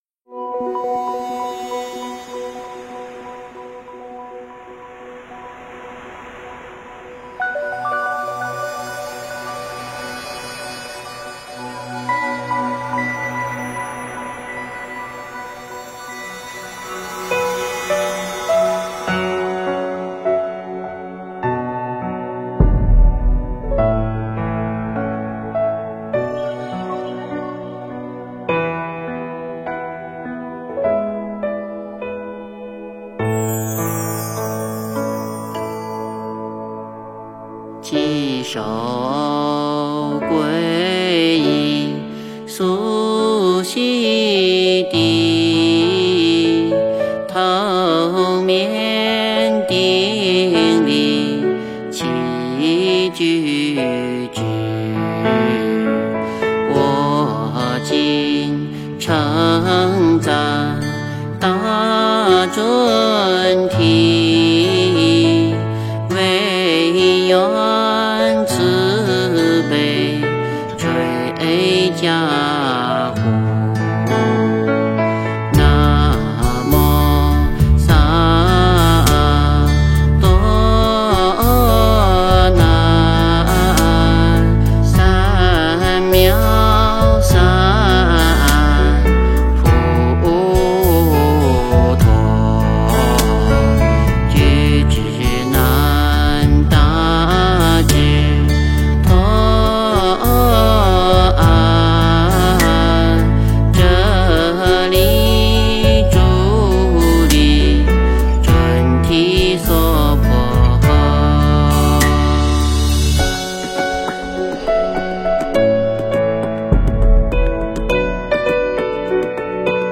诵经
佛音 诵经 佛教音乐 返回列表 上一篇： 地藏赞 下一篇： 观音灵感真言 相关文章 行愿--廖昌永 行愿--廖昌永...